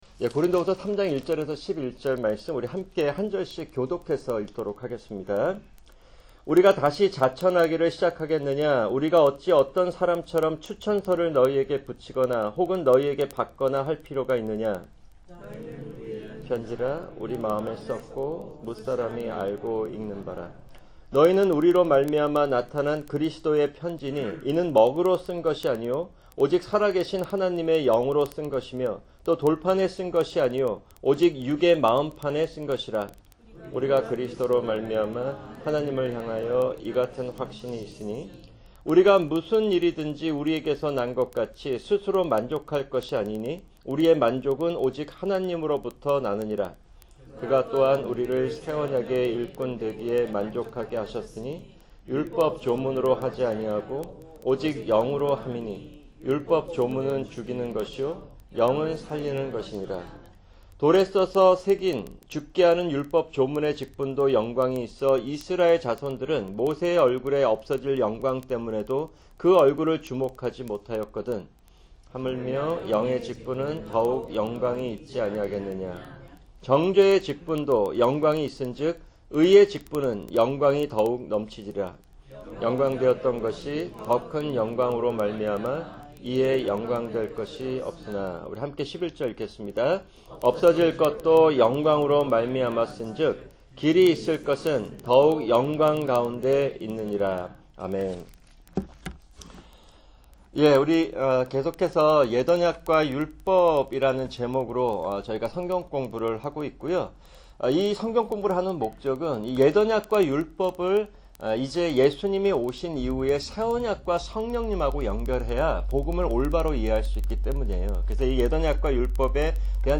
[금요 성경공부] 계시록 20:1-6(2)